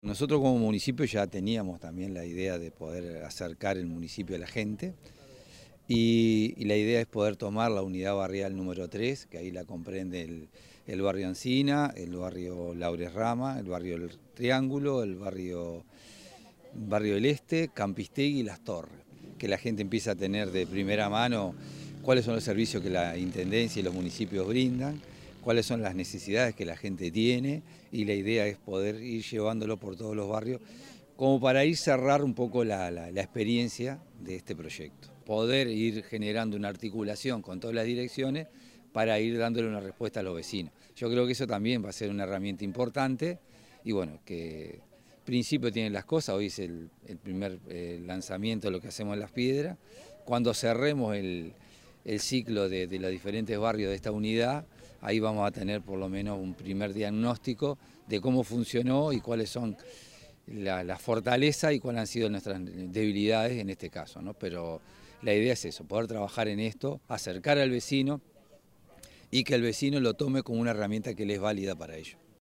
audio_alcalde_gustavo_gonzalez.mp3